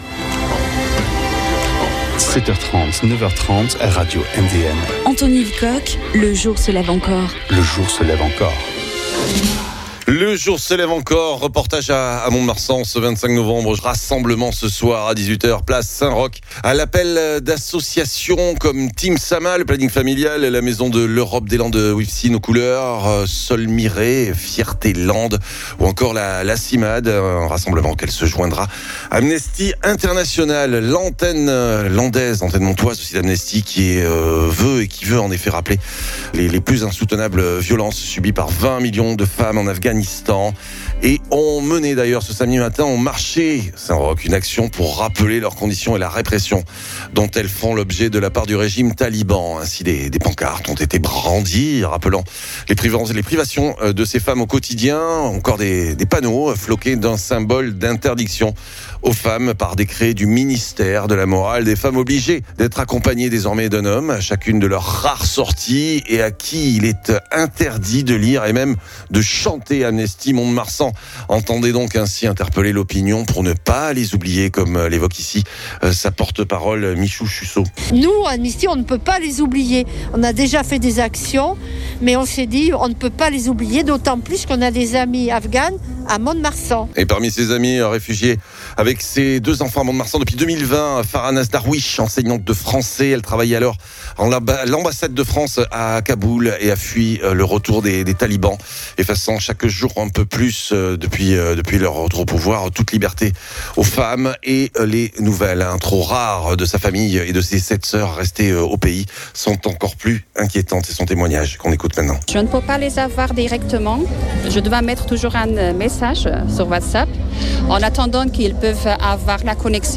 Témoignage / « Les femmes afghanes n’ont même plus le droit de parler entre elles »
L’une d’entre elles réfugiée depuis 2020 dans Les Landes témoigne grâce aux rares nouvelles de ses sœurs encore au pays ….